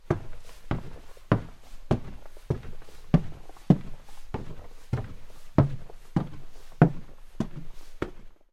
Звуки шагов по лестнице
Шаги по ступеням - Услышьте это